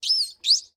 sounds / mob / dolphin / idle4.ogg